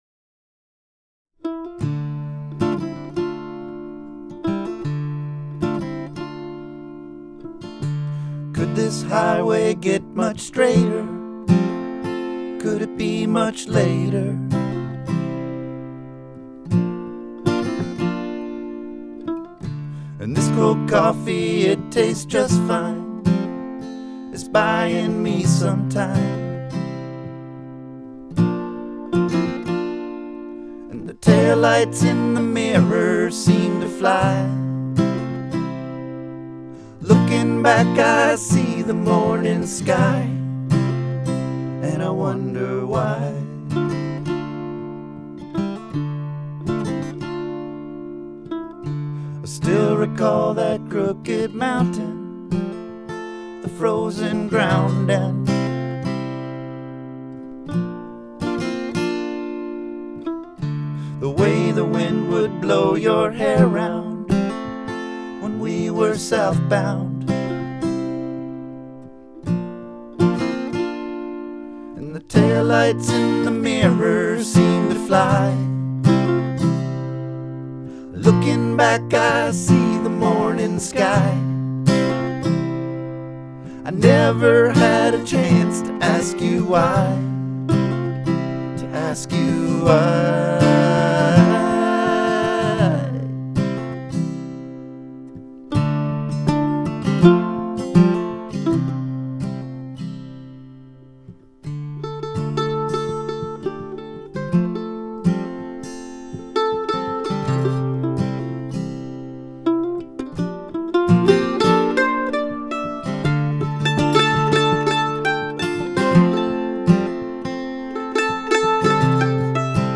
Guitar and vocals
Mandolin, fiddle, and vocals
Tabla and percussion